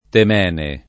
témenos / τέμενος [gr. ant. t $ meno S ]